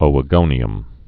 (ōə-gōnē-əm)